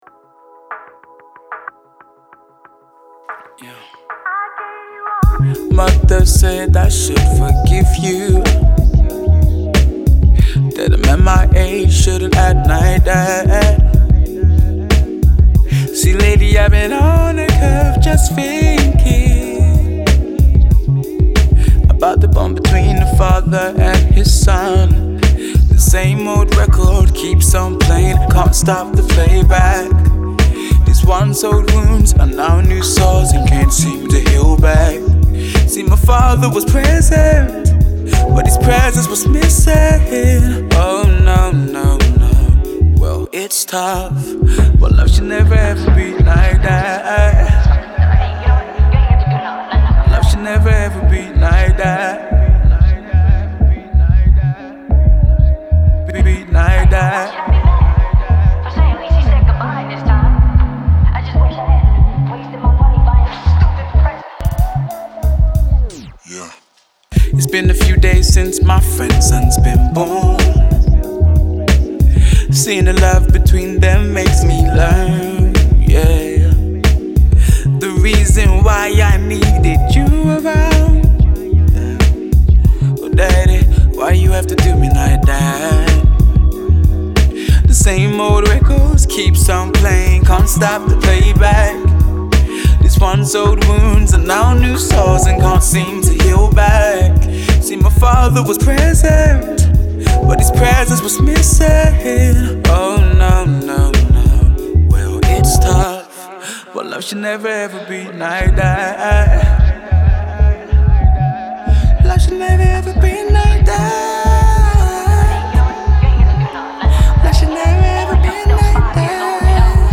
As the songs exudes that early 90’s vibe